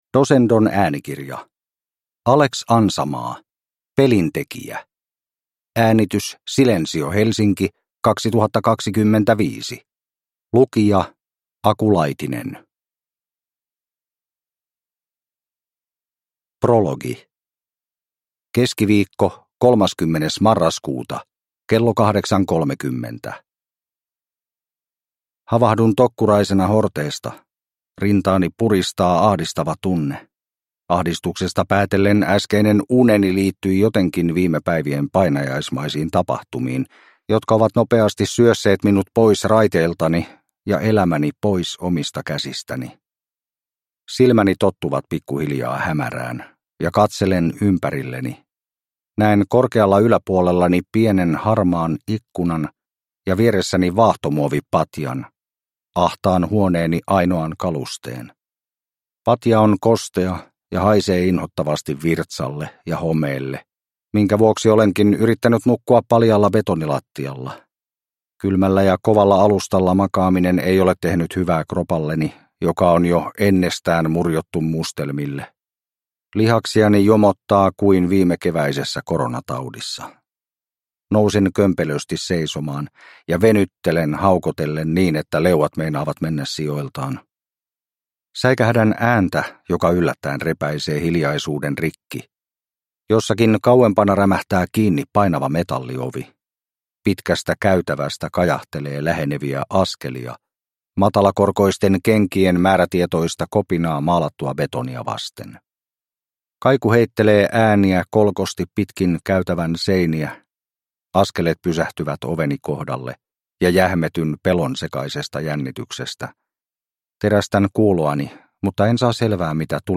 Pelintekijä – Ljudbok